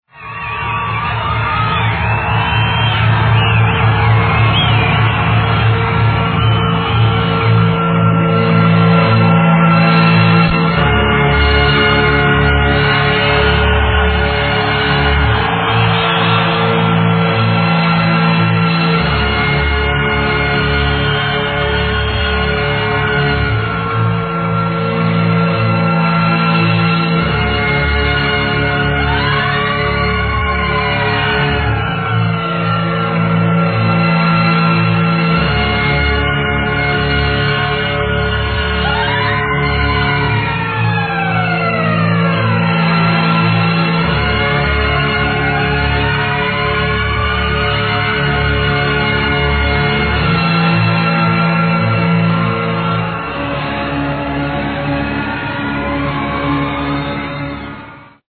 unknown orchestral track (sounds like smthn John Williams would write)
I Think it comes from a movie soundtrack but I was unable to pinpoint which soundtrack..